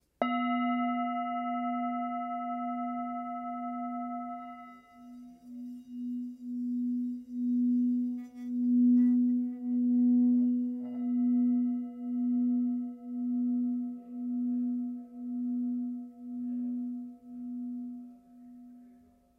Tibetská mísa Non střední
Tepaná tibetská mísa Non o hmotnosti 1080 g. Mísa je včetně paličky s kůží!
Zvuk tibetské mísy Non si můžete poslechnout
Lahodné dlouhotrvající tóny tibetské mísy nám umožňují koncentrovat naši mysl, relaxovat a uvolnit naše tělo.
tibetska_misa_s33.mp3